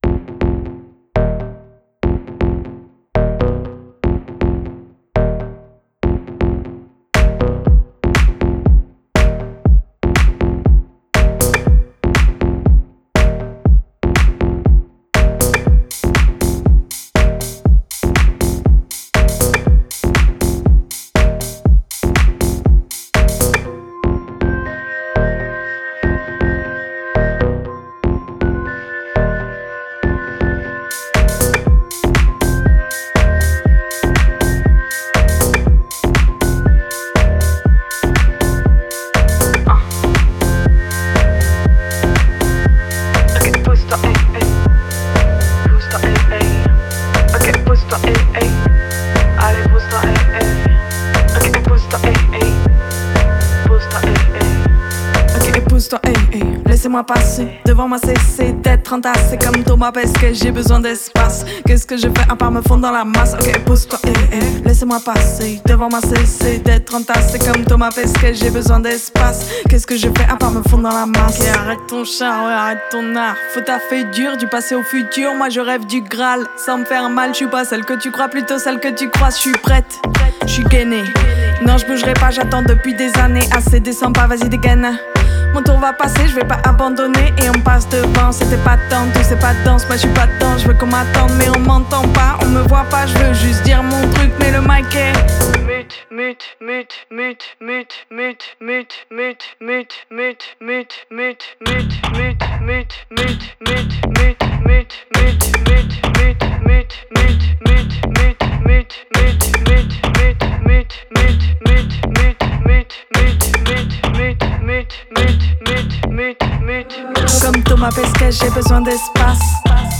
BEATBOX • RAP • ELECTRO